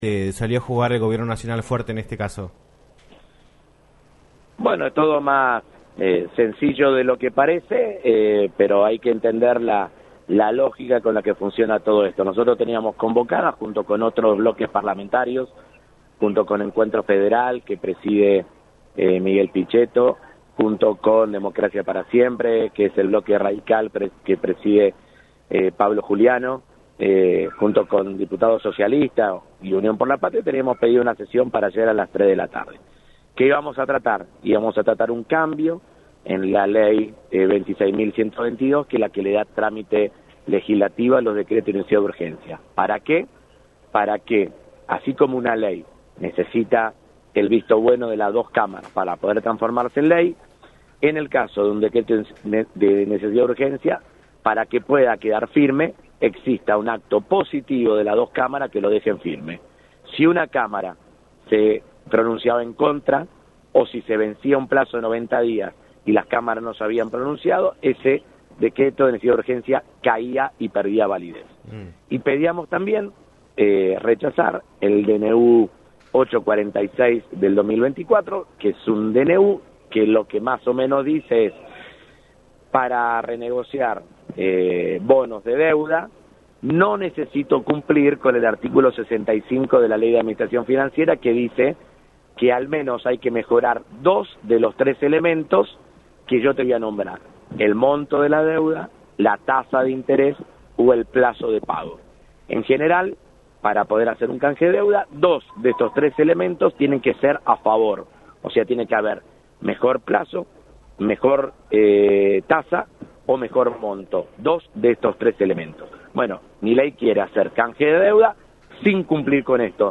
Germán Martínez, diputado nacional del bloque de Unión Por la Patria, habló en "Un Millón de Guanacos" por LaCienPuntoUno sobre el trabajo legislativo que viene llevando adelante la Cámara de Diputados y la falta de discusión para el presupuesto del año que viene.